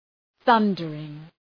Προφορά
{‘ɵʌndərıŋ}